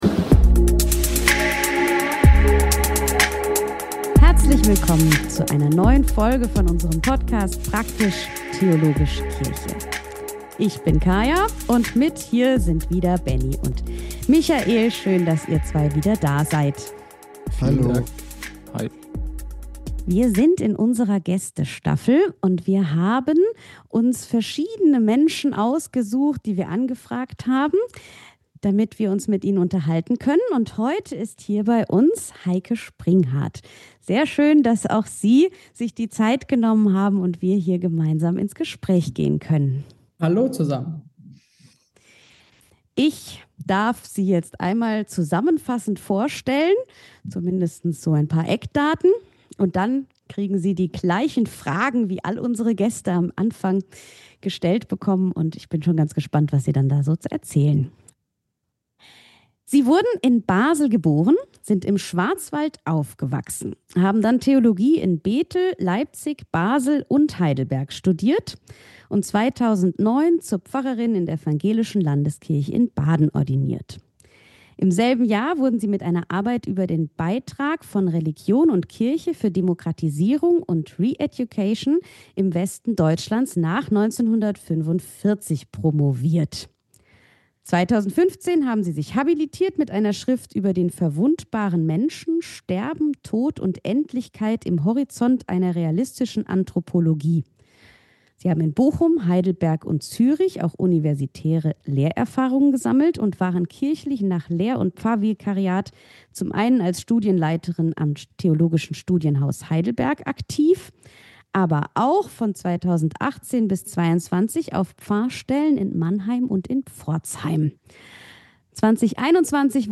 Wir reden mit ihnen über das, was sie begeistert und wo sie Chancen und neue Perspektiven für die Kirche sehen. Heute zu Gast: Bischöfin Heike Springhart.